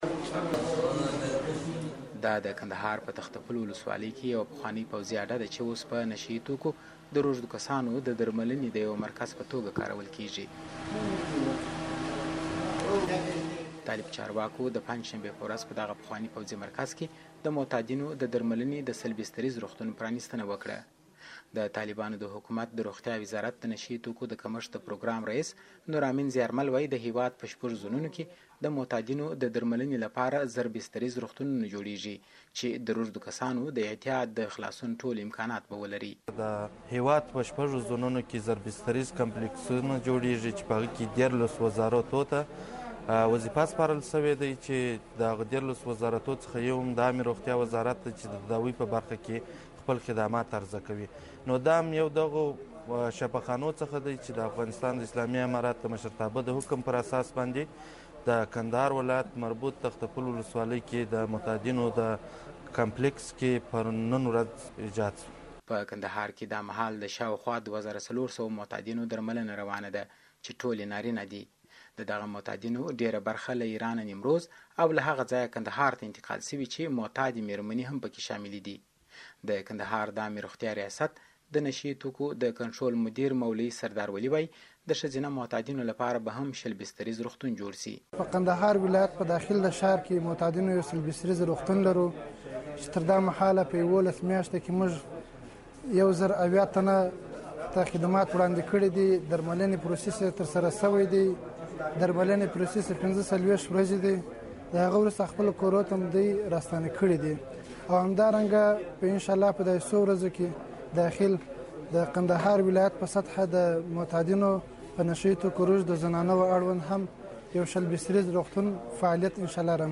کندهار کې د معتادیو راپور